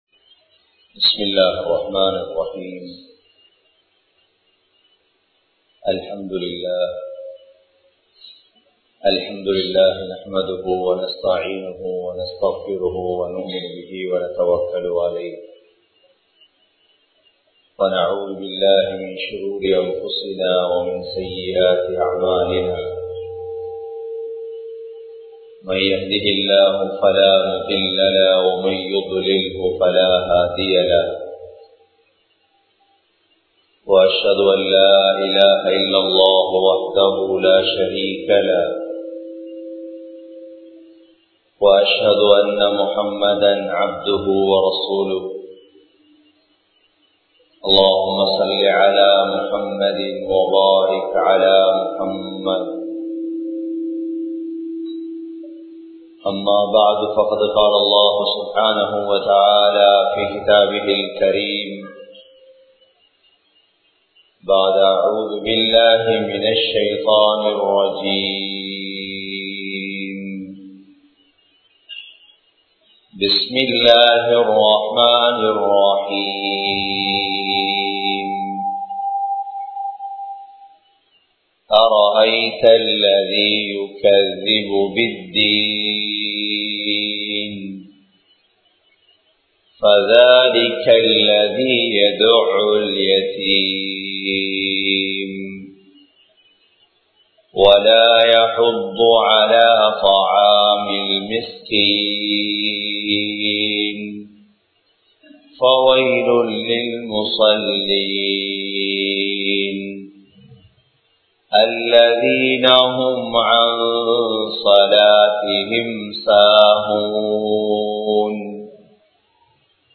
Tholukaiyai Vidupavarkale!தொழுகையை விடுபவர்களே! | Audio Bayans | All Ceylon Muslim Youth Community | Addalaichenai
Jariyah Jumua Masjith